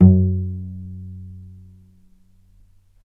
vc_pz-F#2-mf.AIF